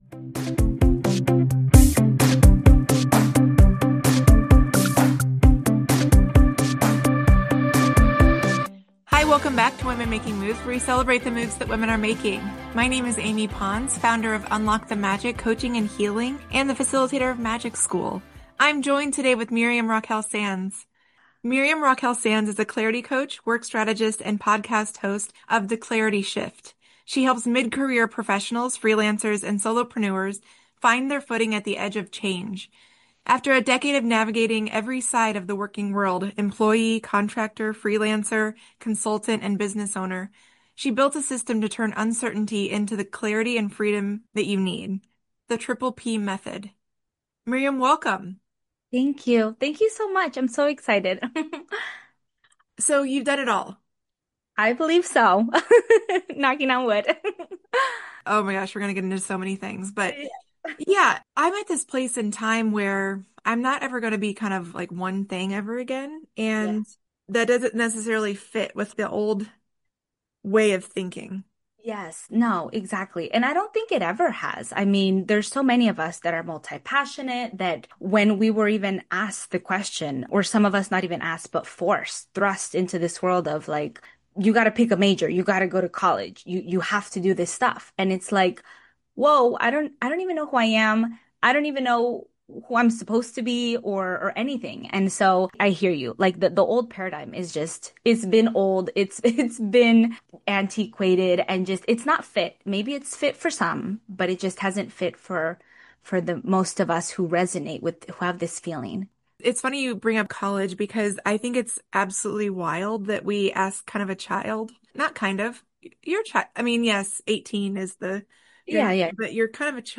Introduction and Guest Welcome